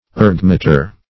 Meaning of ergmeter. ergmeter synonyms, pronunciation, spelling and more from Free Dictionary.
ergmeter.mp3